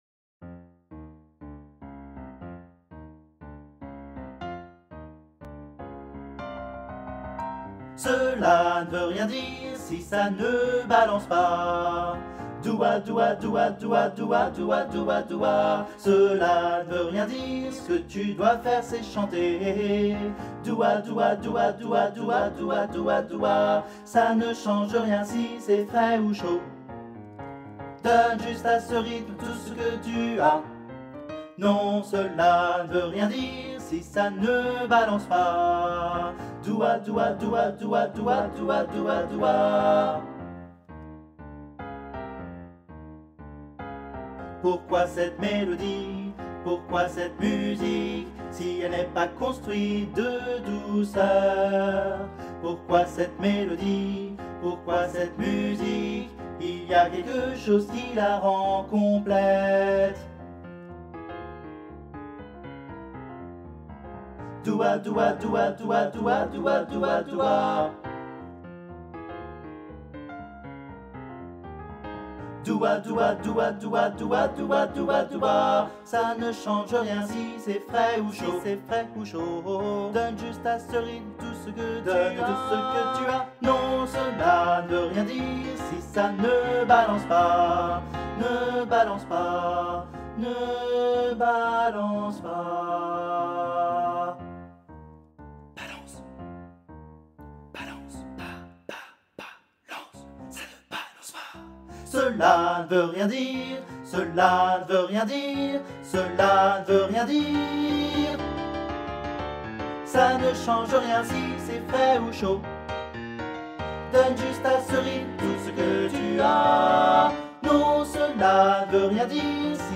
- Oeuvre pour choeur à 3 voix mixtes (SAH)
MP3 versions chantées
Tutti